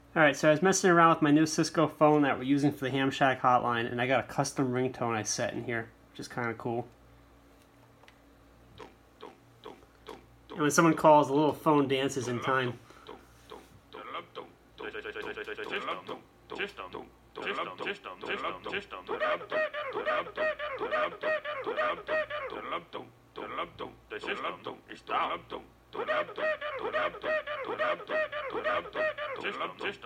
Custom ringtone for Hamshack Hotline sound effects free download